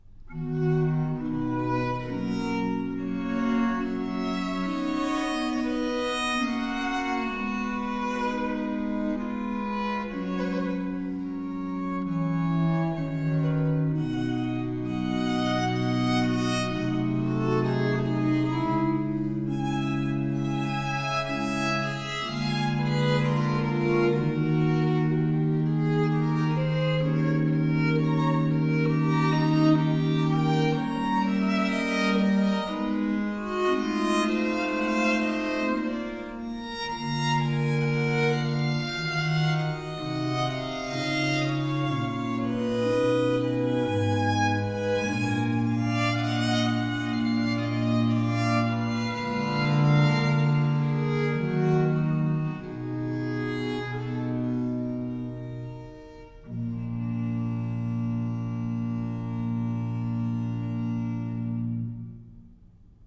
Soundbite 3rd Movt
for 2 Violins and Cello